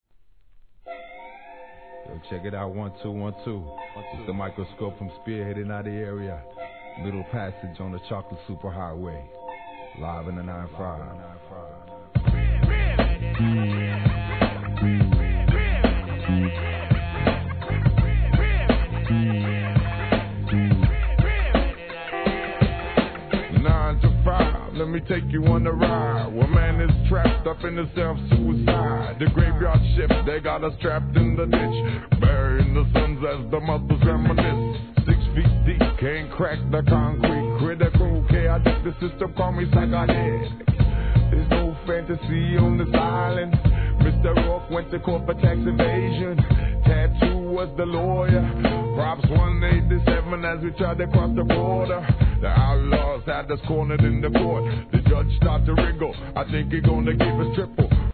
HIP HOP/R&B
打ち込みと生音の好バランスに加えて、語りかけるような独特のフロウが人気!!